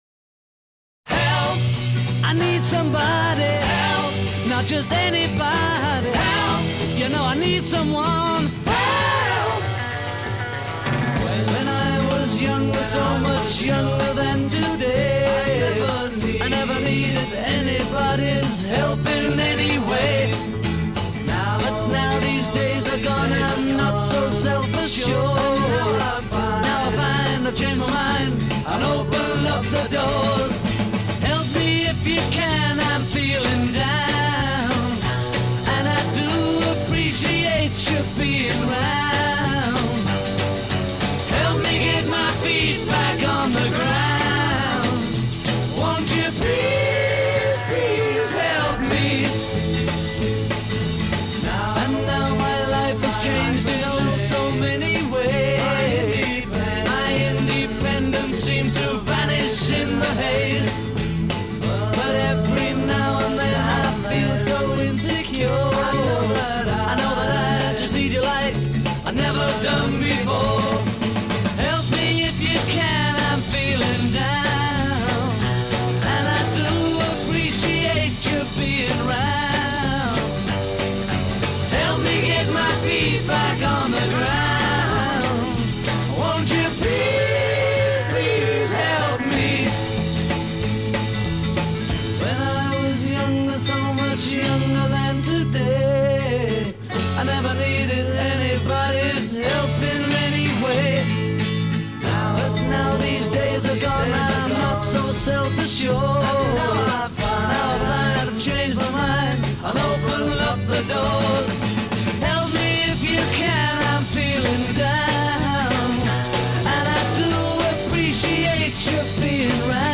voice & rhythm guitar
voice & bass guitar
lead guitar
drums